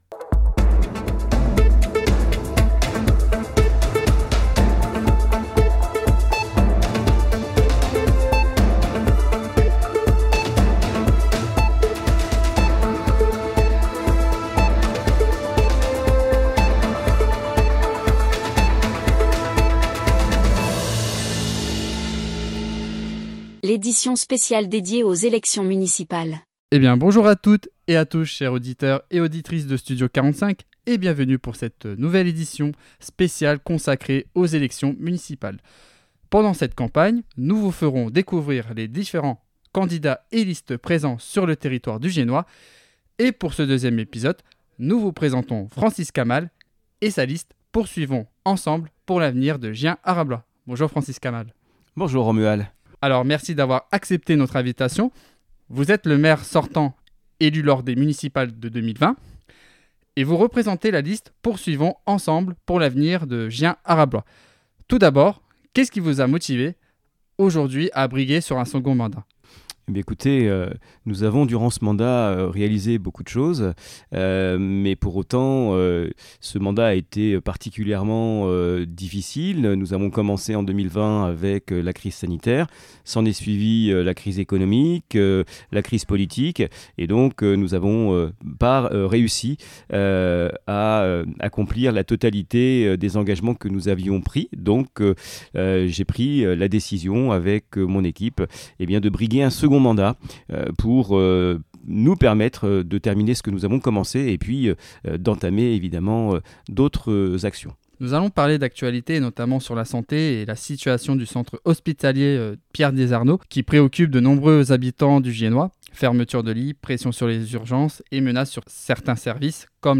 Cadre de vie, dynamisme économique, services de proximité, mobilité, vie associative… une interview pour mieux comprendre sa vision et ses propositions pour l’avenir de notre territoire.
Un échange clair et direct, pour permettre à chacun de se faire son opinion à l’approche du scrutin.